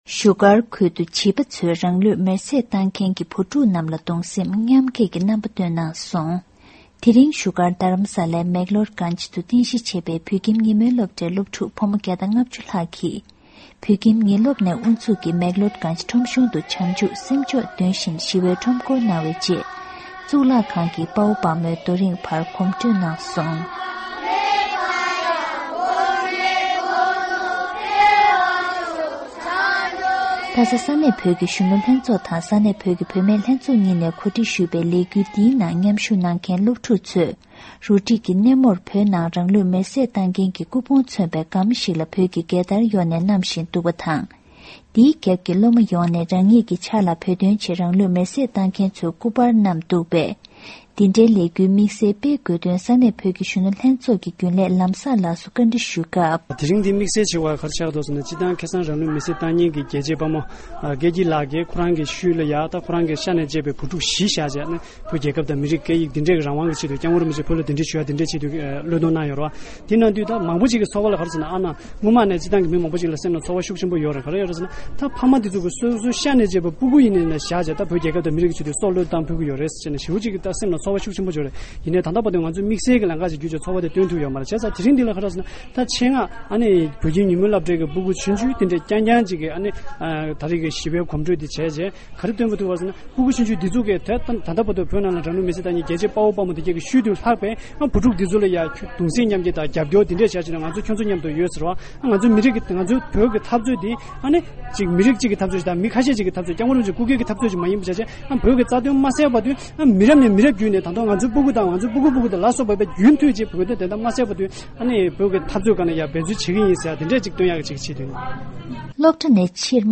ས་གནས་ནས་བཏང་བའི་གནས་ཚུལ་ཅིག